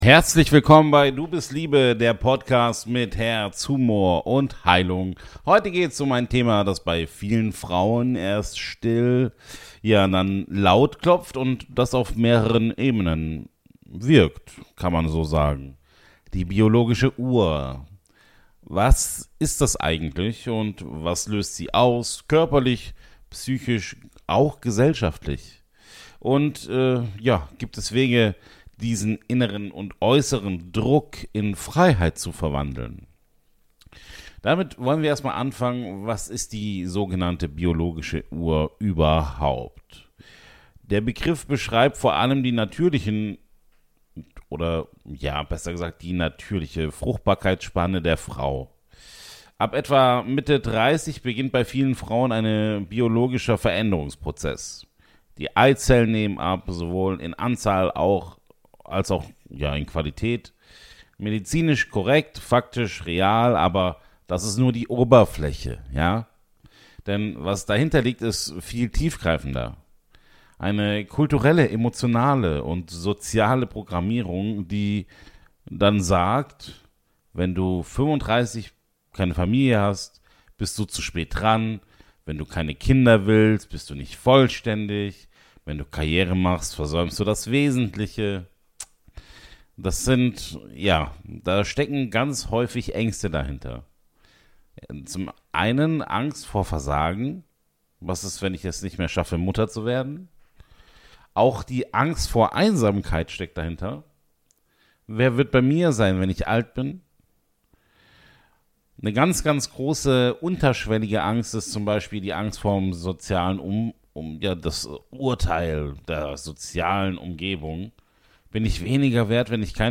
Inklusive Mini-Meditation, Reflexionsfragen & liebevoller